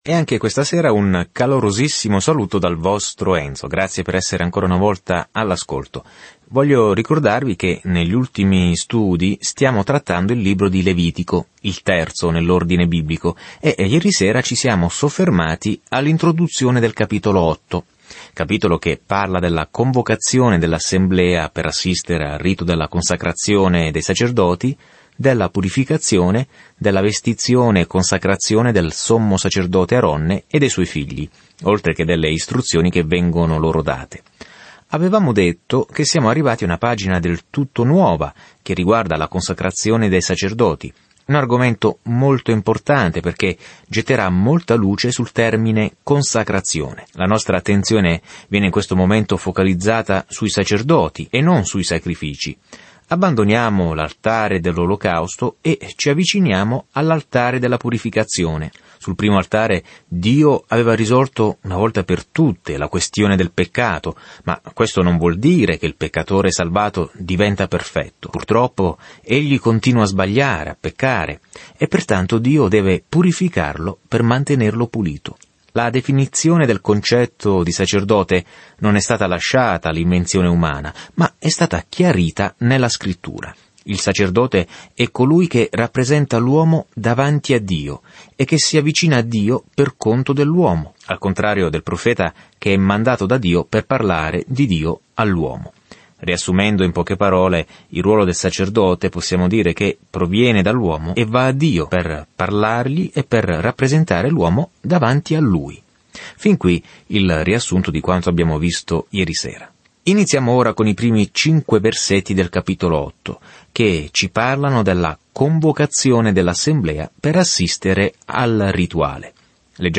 Viaggia ogni giorno attraverso il Levitico mentre ascolti lo studio audio e leggi versetti selezionati della parola di Dio.